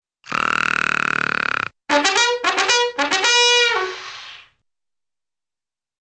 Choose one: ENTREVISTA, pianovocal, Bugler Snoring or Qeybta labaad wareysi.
Bugler Snoring